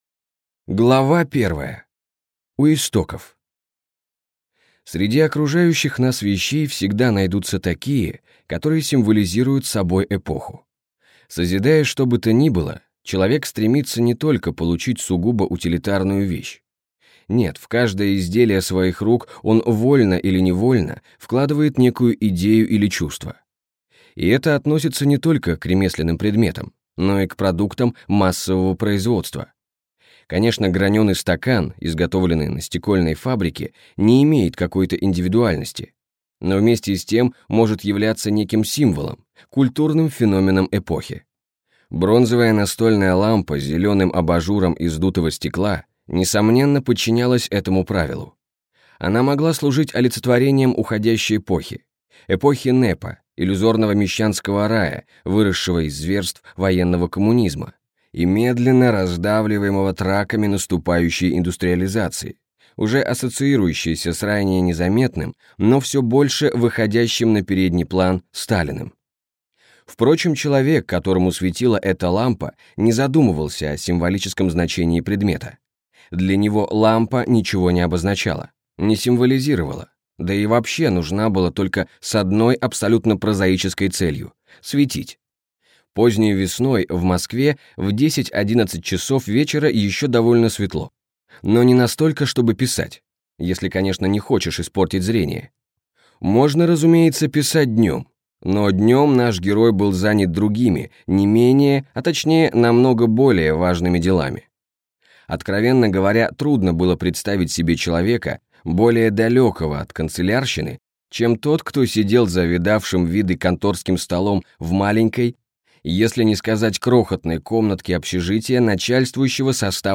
Аудиокнига Белый квадрат. Лепесток сакуры | Библиотека аудиокниг